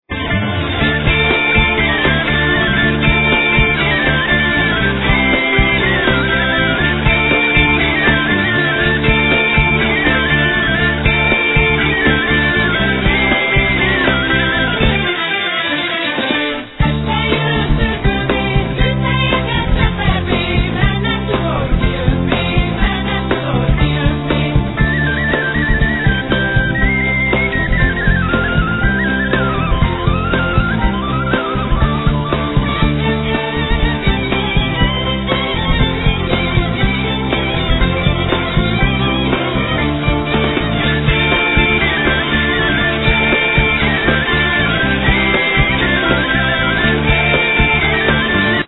Vocals, Bass
Flute, Turk-pipe